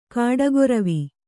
♪ kāḍagoravi